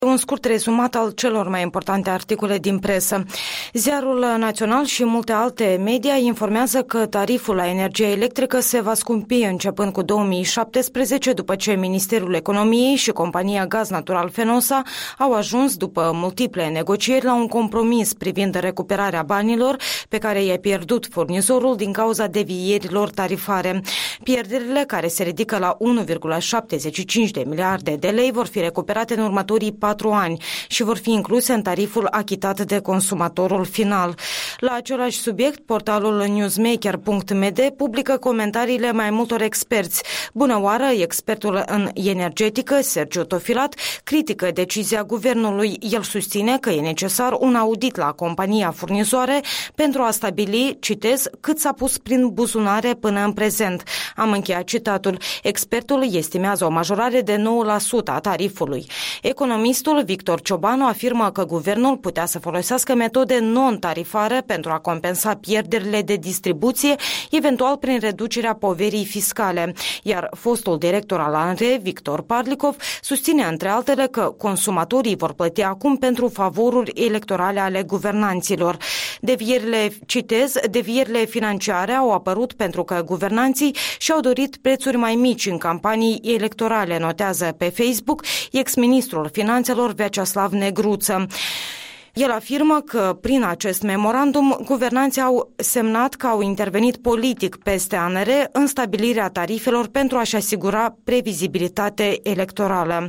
Revista presei matinale